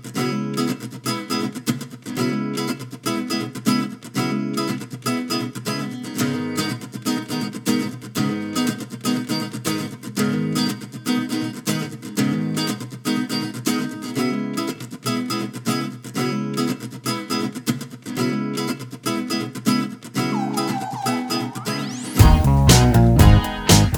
Minus Guitars Except Acoustics Pop (2010s) 3:53 Buy £1.50